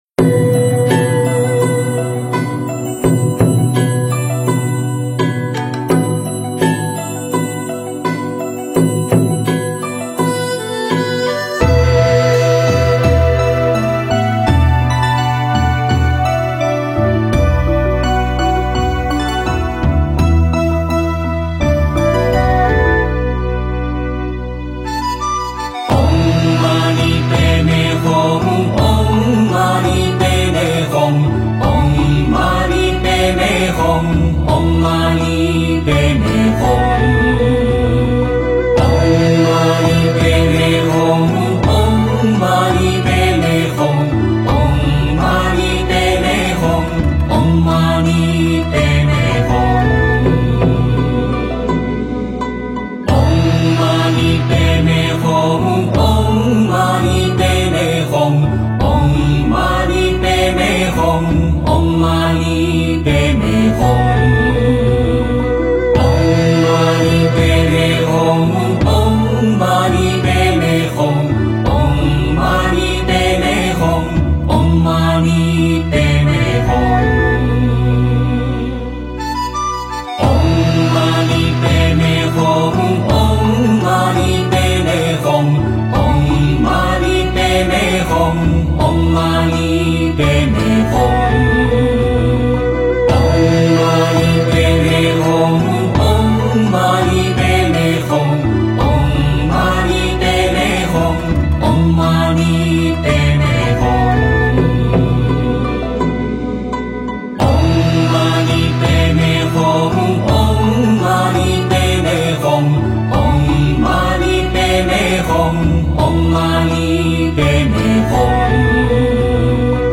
六字大明咒(男女合唱)--新韵传音
六字大明咒(男女合唱)--新韵传音 经忏 六字大明咒(男女合唱)--新韵传音 点我： 标签: 佛音 经忏 佛教音乐 返回列表 上一篇： 道德经--新韵传音 下一篇： 弥勒救苦真经--新韵传音 相关文章 无量寿经--女众寺院版 无量寿经--女众寺院版...